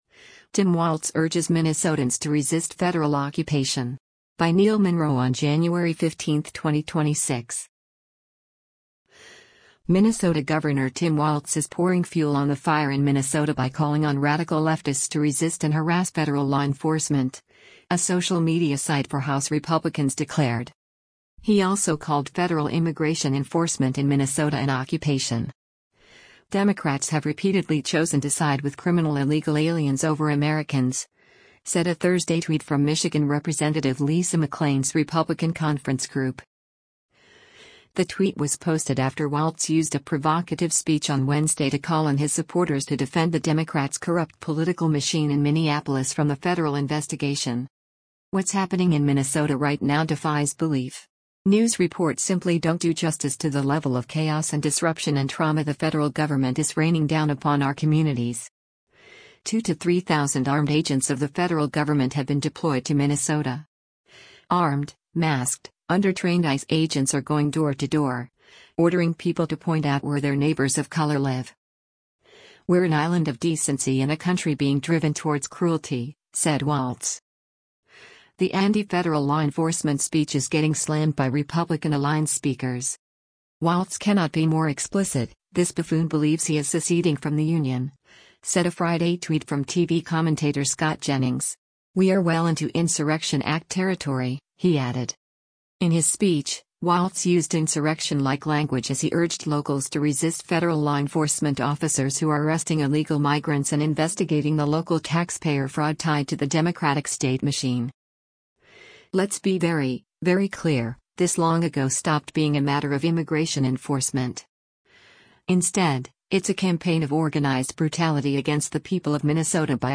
In his speech, Walz used insurrection-like language as he urged locals to resist federal law enforcement officers who are arresting illegal migrants and investigating the local taxpayer fraud tied to the Democratic state machine.